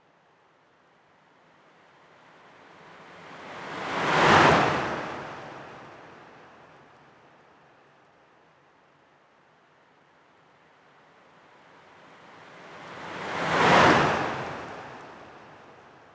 Waves.wav